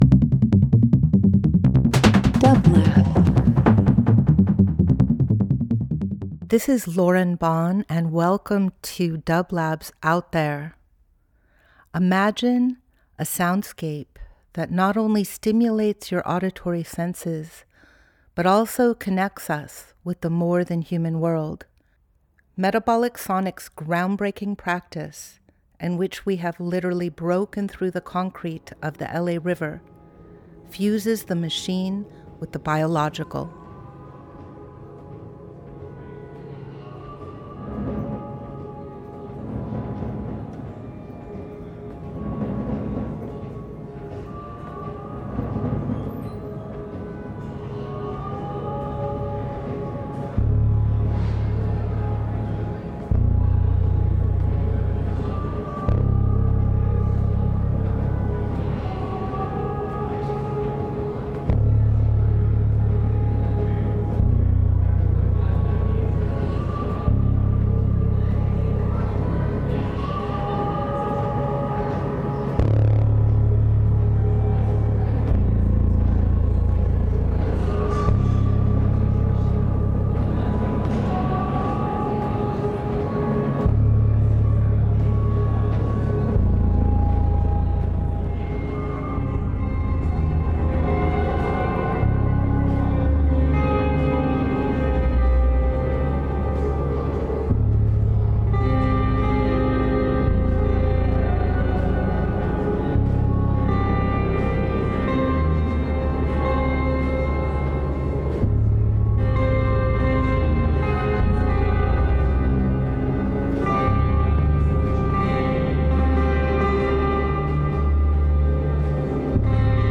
Experimental Field Recording Rock
selections from a jam that took place on March 18, 2019.